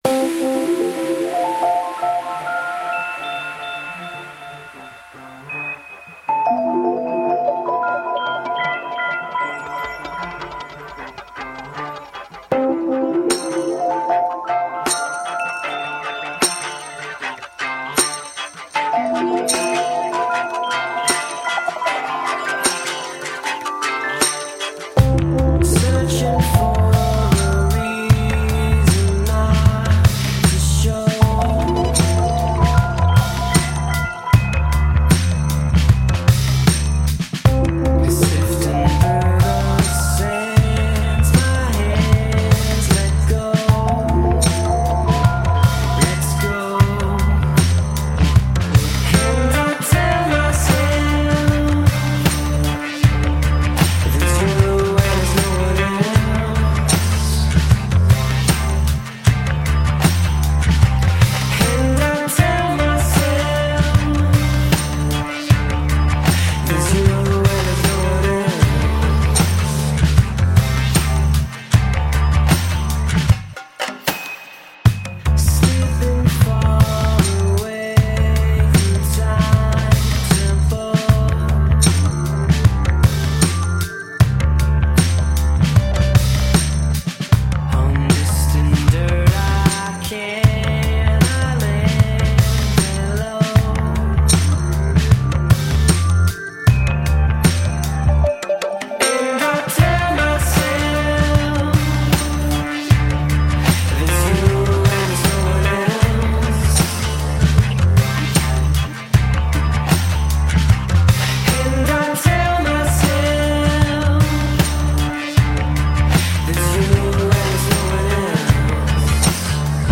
other worldly and spacey
Tagged as: Electro Rock, Rock, Synth